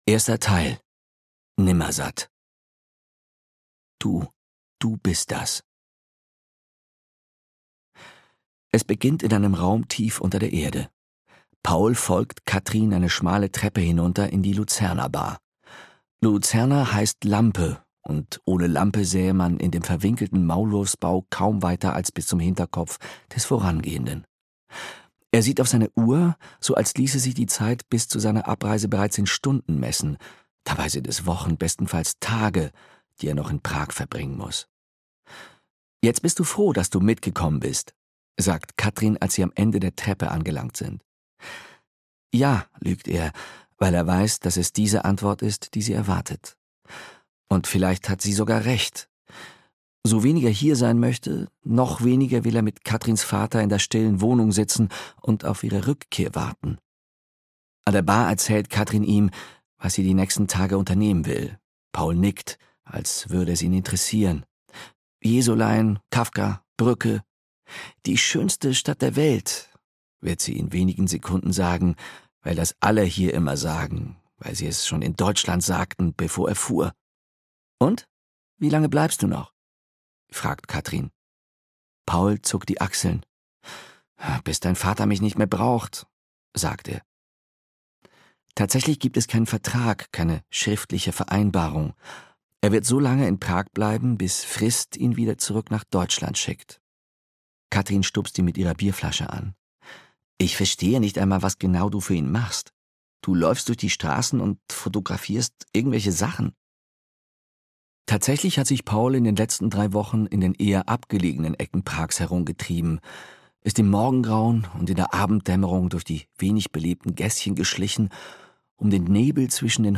Der Dieb in der Nacht - Katharina Hartwell - Hörbuch